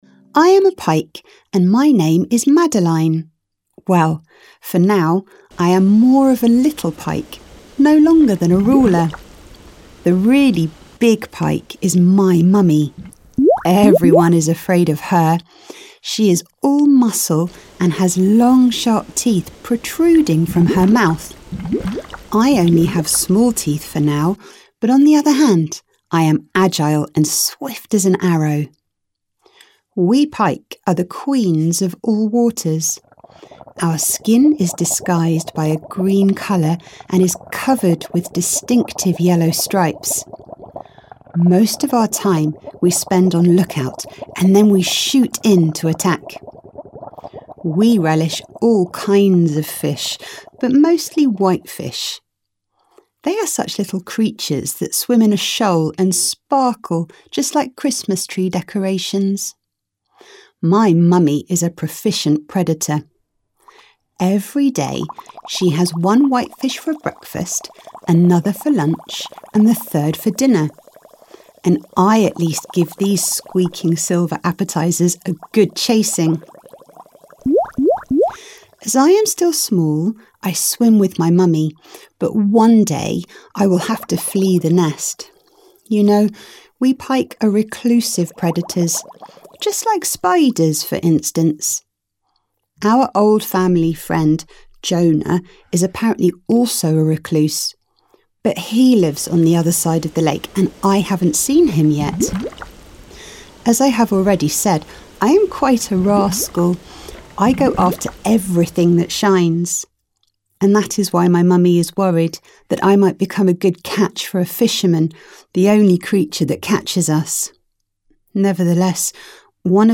Ukázka z knihy
Knihu čte rodilý mluvčí. Hlas je doplněn krásnými hudebními melodiemi. Pohádkový příběh se šťastným koncem.Madeleine the Little Pike is a rascal.